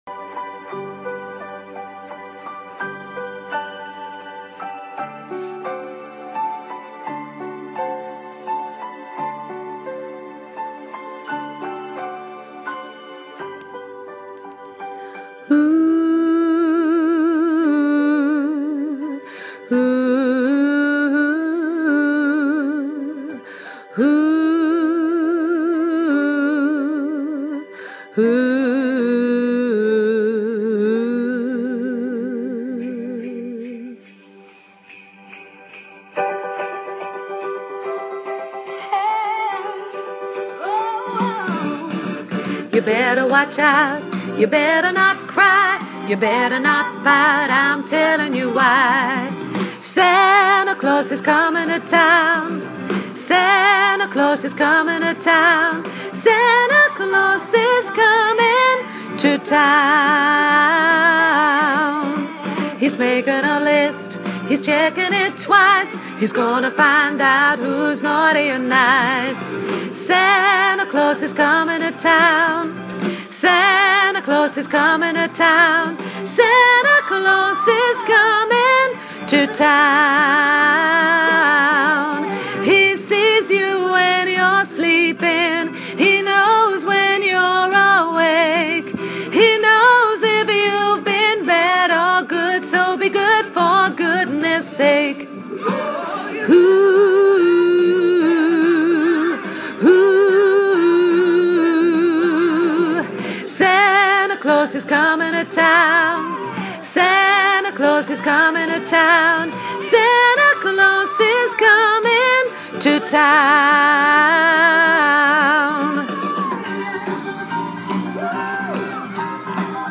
koortje tenor